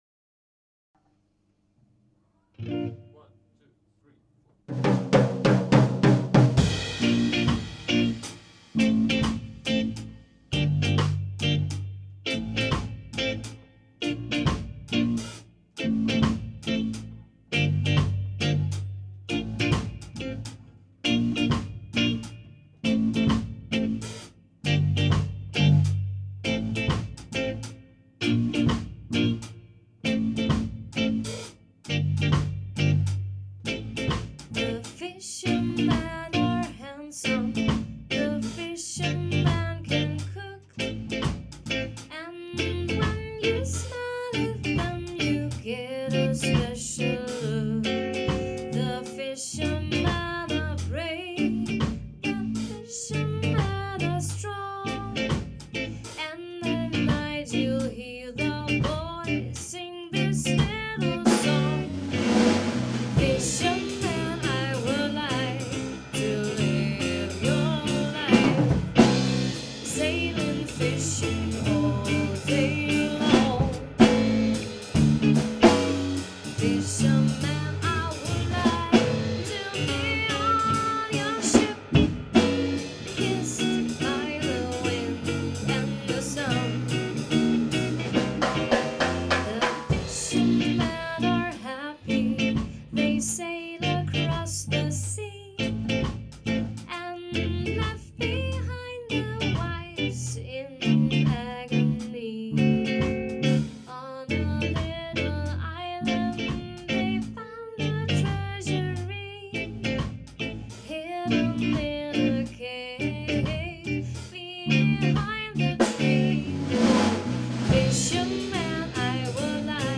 Slow Swing